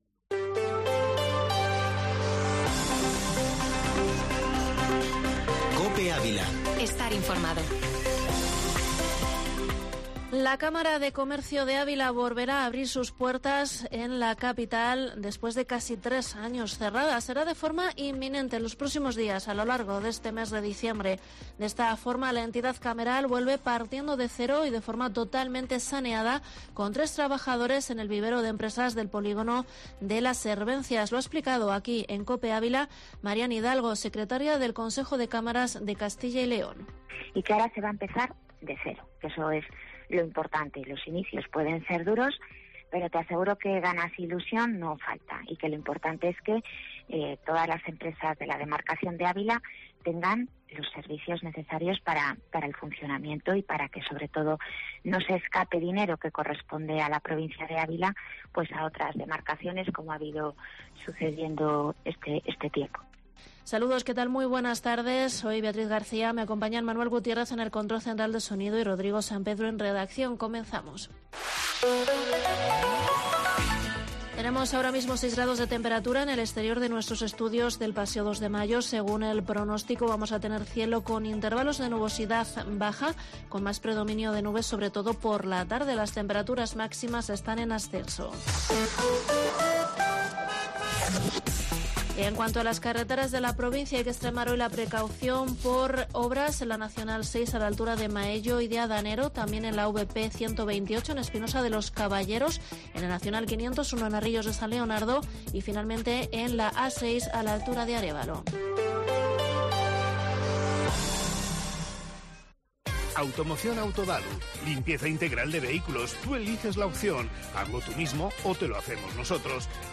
Informativo Mediodía Cope en Avila 30/11/2021